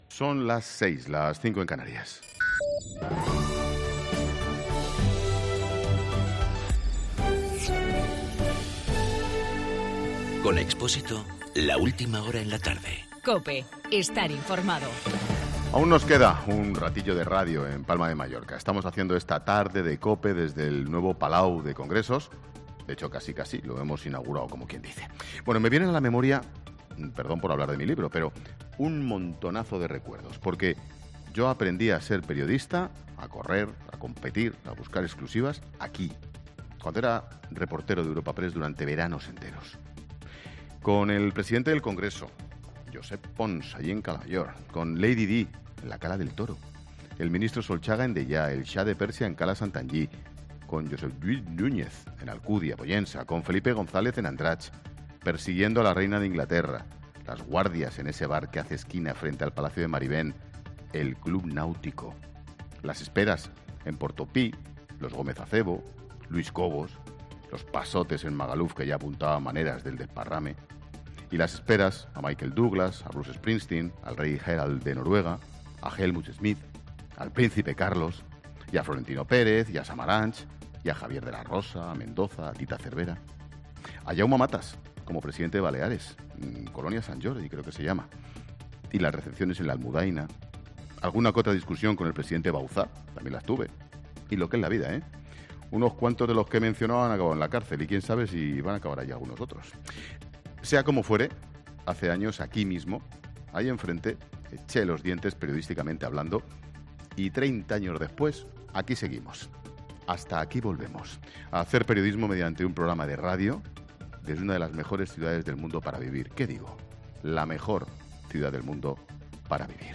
AUDIO: Ángel Expóisito desde el Palau de Congresos de Palma de Mallorca.